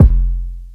• 2000s Tight Low End Kick F Key 44.wav
Royality free bass drum tuned to the F note. Loudest frequency: 109Hz